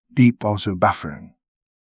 deep-bozo-buffering.wav